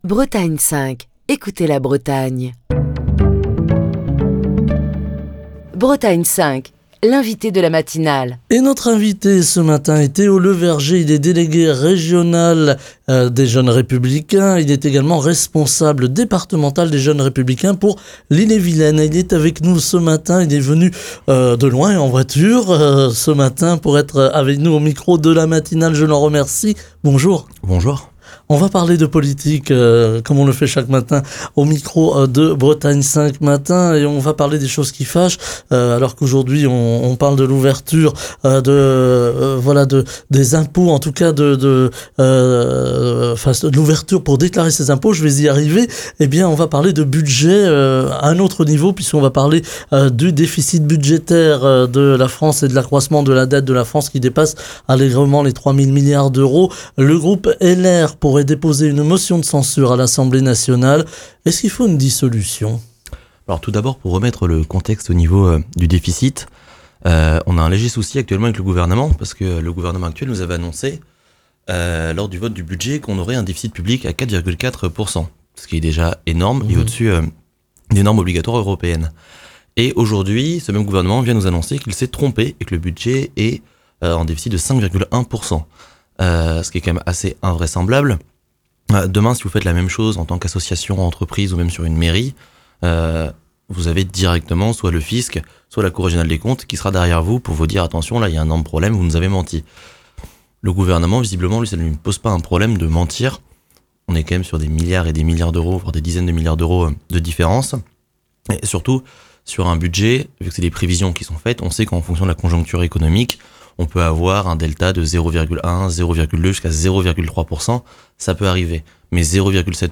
Émission du 11 avril 2024.